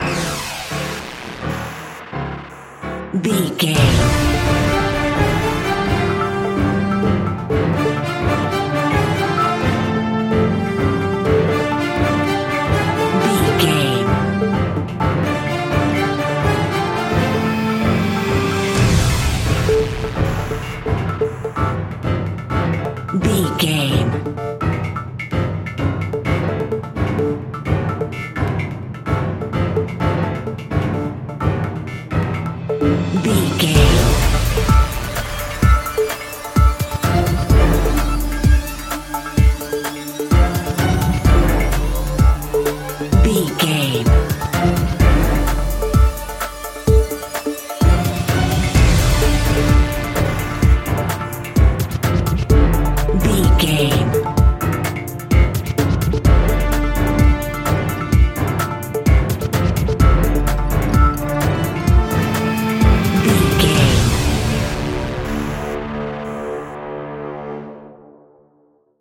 Chase Scene Music.
Aeolian/Minor
scary
tension
ominous
dark
suspense
eerie
industrial urban
piano
percussion
brass
drum machine
synth
pads